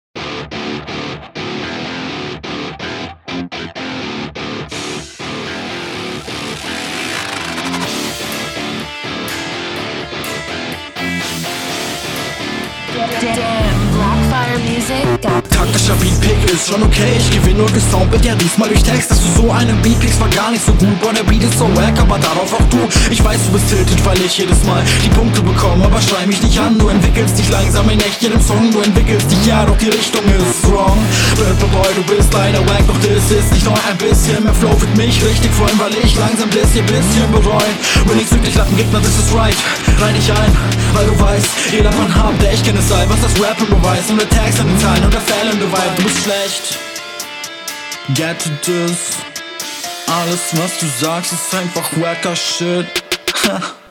Flow ist eigentlich ziemlich solide, aber der Stimmeinsatz ist hier nicht so gut auf dem …
flowst sehr dope auf dem beat, finde leider die stimme bissl zu leise gemischt, an …